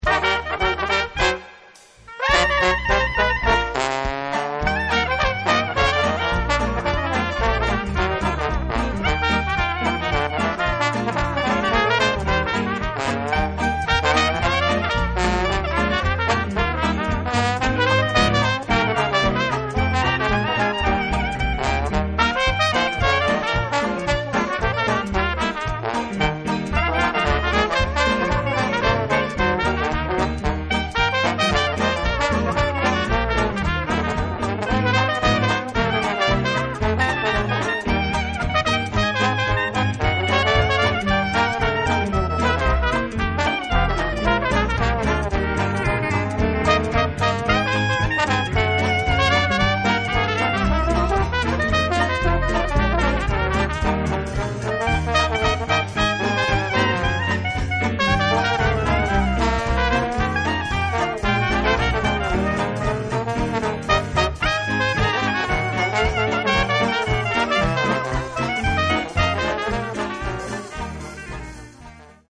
• Outstanding traditional Dixieland jazz band in Bristol
Lively, energetic and simply oozing class, this exceptional trad jazz band successfully captures the Mardi Gras spirit of The Big Easy, the birthplace of jazz, at its very best.
The seven-strong full band is comprised of trumpet, clarinet, trombone, guitar, piano, bass and drums, although the line-up can easily be altered to suit your event.